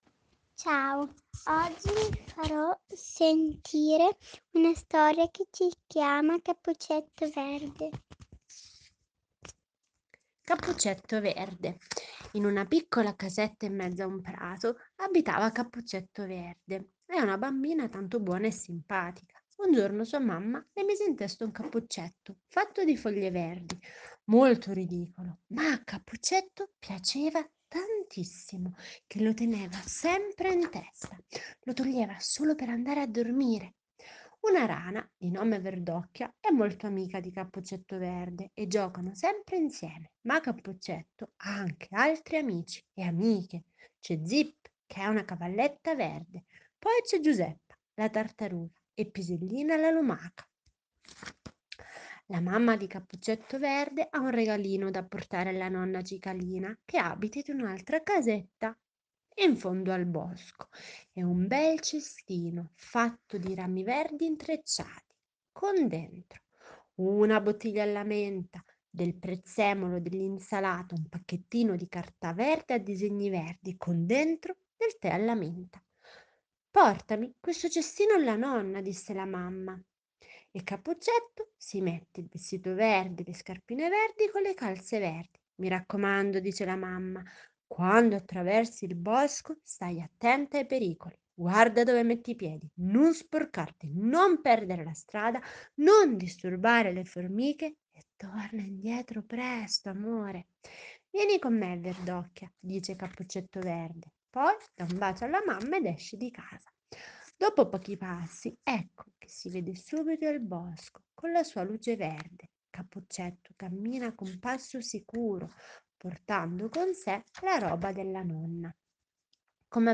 Audio libri
Ci piace l’idea di fare una coccola sonora ai vostri bimbi sentendo la storia raccontata dalle loro maestre…